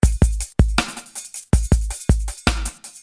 Drumnbass drumloops soundbank 1
Dumnbass rythm 6